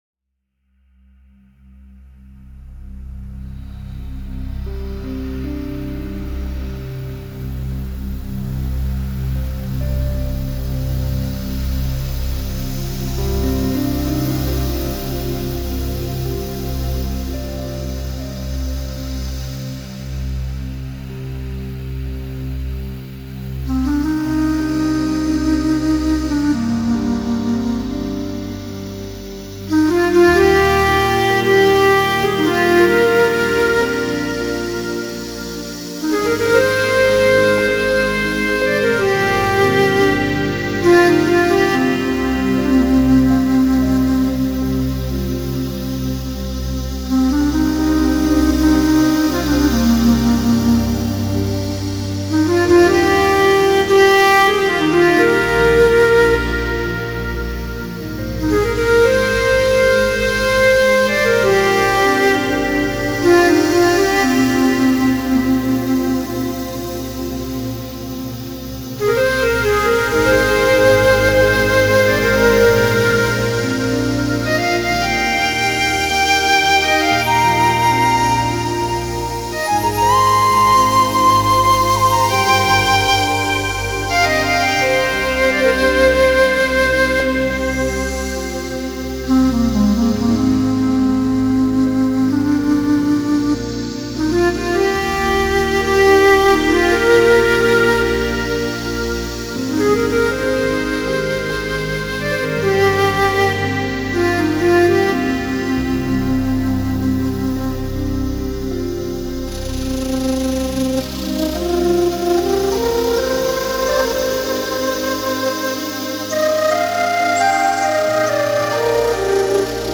传统的爱尔兰音乐和古典音乐，受Mike Oldfield的